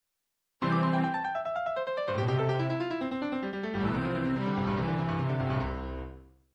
このパッセージの終わりの部分はこんな風になる。チェロも下降分散和音になっている。